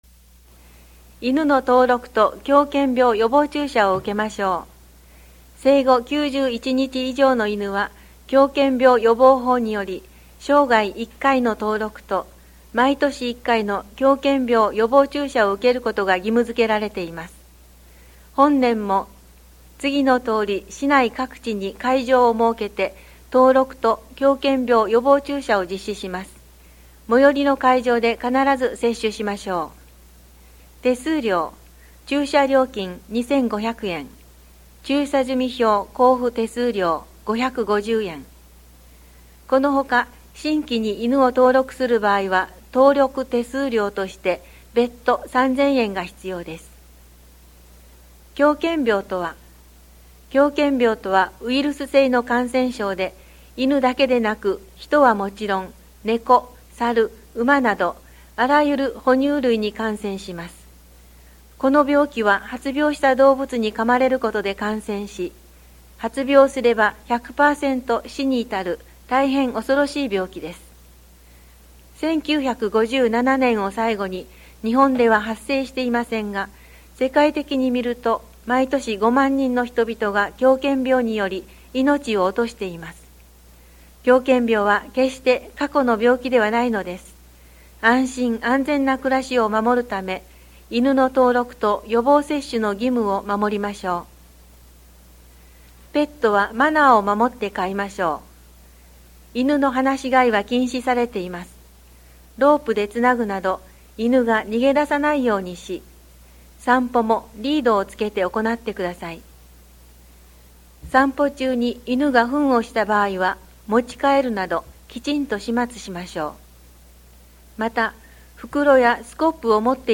また、音訳ボランティア「エポカル武雄フレンズ」のご協力により、音読データをMP3形式で提供しています。